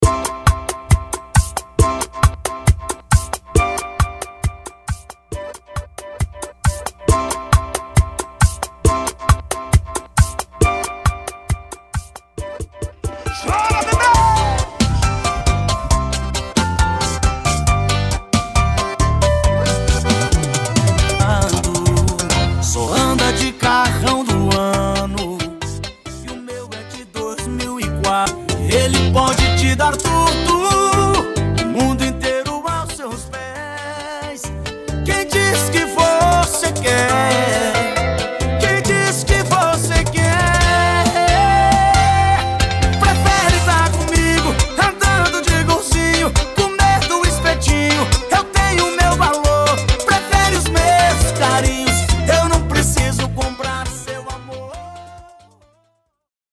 BPM 136 / Bachata